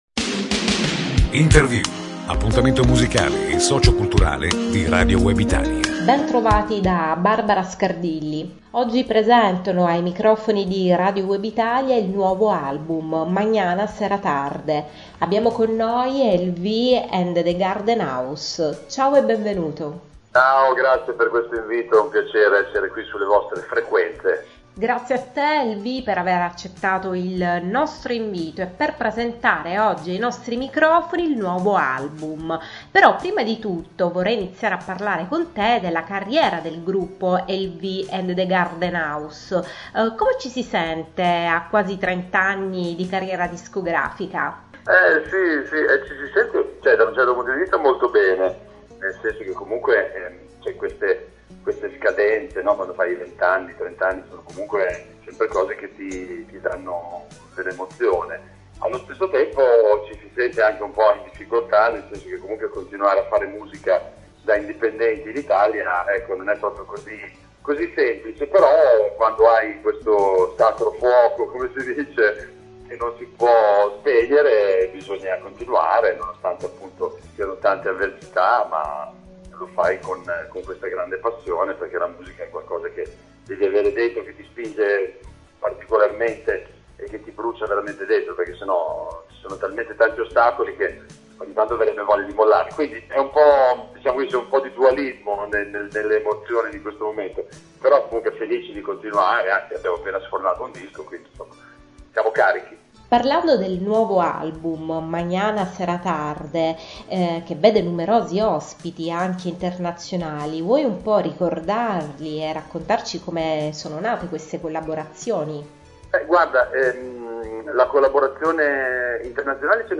intervista allo storico combo bolognese